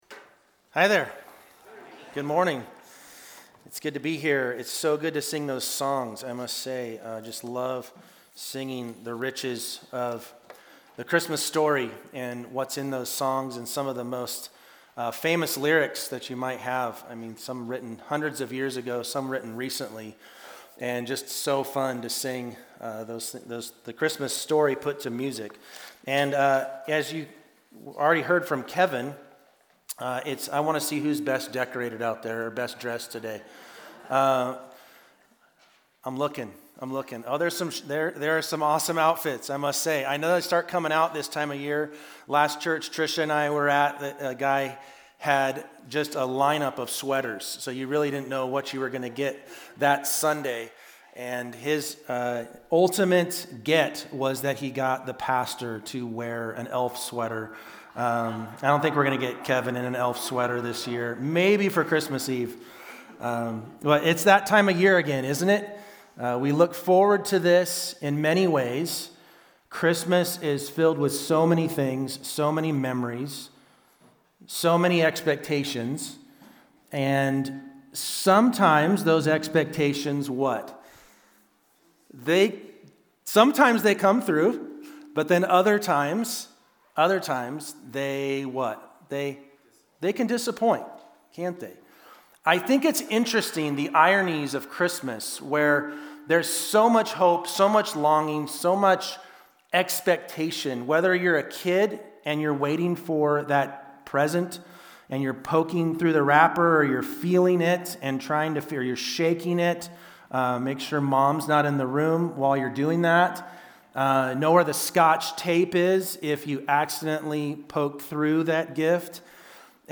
1 The Five Convictions of Those Who Transform the World | Acts 7:54-8:4 | Live Sent 42:54 Play Pause 20d ago 42:54 Play Pause Play later Play later Lists Like Liked 42:54 Though the first few chapters of Acts are filled with baptisms and miracles, the pivotal point of the book—the stoning of Stephen—shows how God uses ordinary believers to display his power and accomplish his mission amidst persecution. In today’s message from Acts 7 and 8